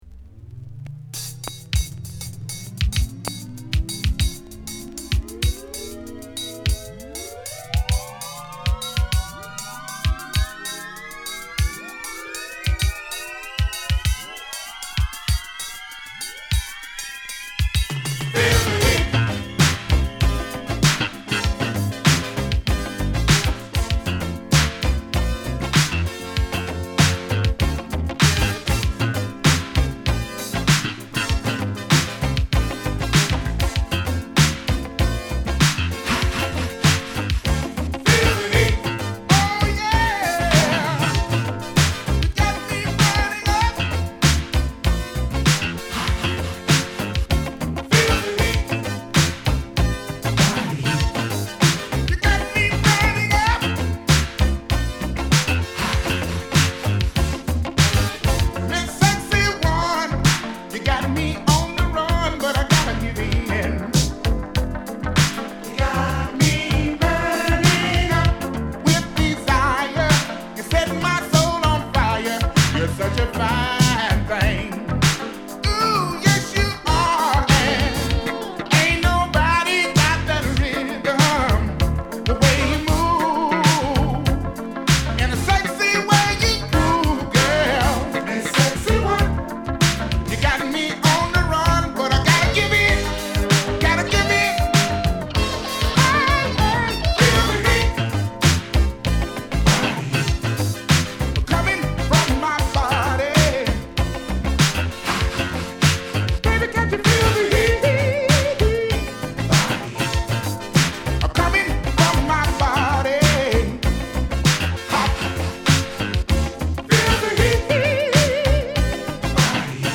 ＊盤面擦れあり